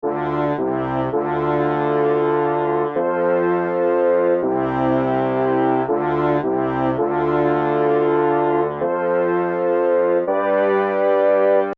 Here is what my second and third horn sound like.
lush_horns3.mp3